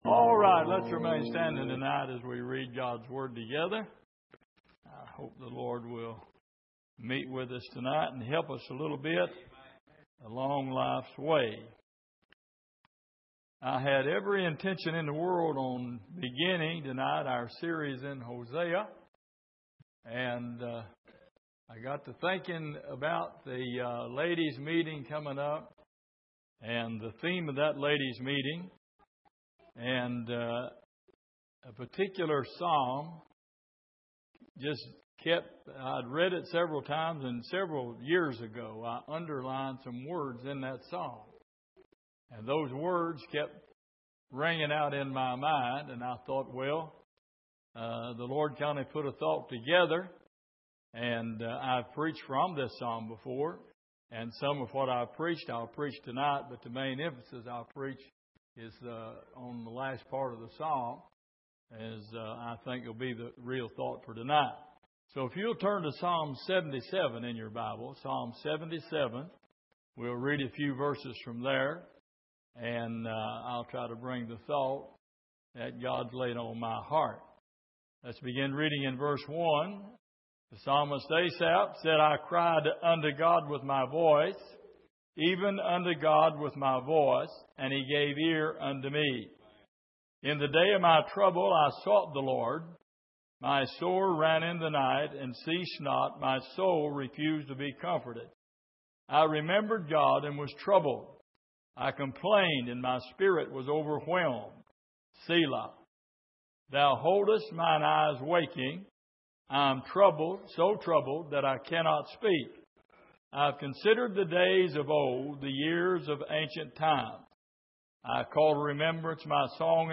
Exposition of the Psalms Passage: Psalm 77:1-13 Service: Sunday Evening Asaph’s Reflections « Bound In The Prison Of Self Why Are You Here?